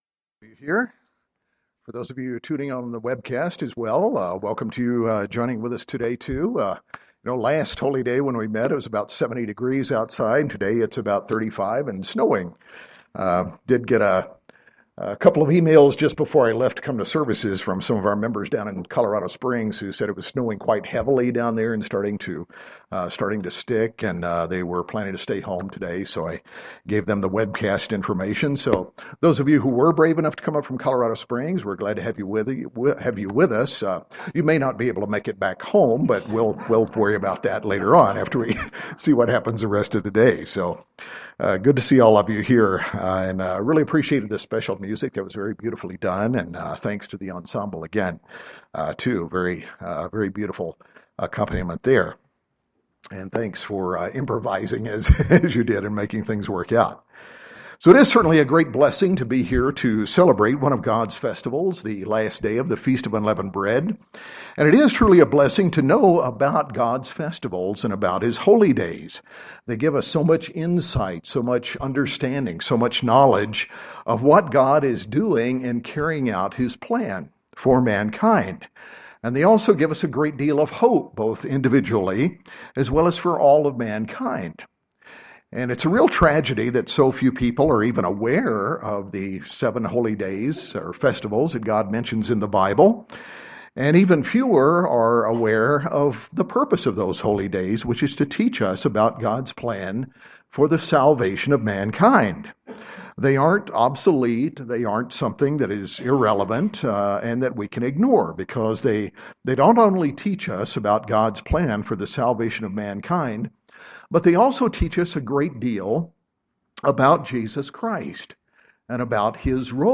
And while many assume that Jesus Christ did away with these Holy Days, the facts of the Bible show that in reality He is at the center of each of them and they teach us a great deal about Him and His role in God's plan for salvation. In this sermon, we go through the biblical festivals in order and see how each teaches us about Jesus Christ.